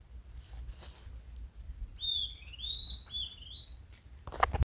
Estalador (Corythopis delalandi)
Nome em Inglês: Southern Antpipit
Condição: Selvagem
Certeza: Fotografado, Gravado Vocal